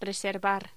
Locución: Reservar
Sonidos: Voz humana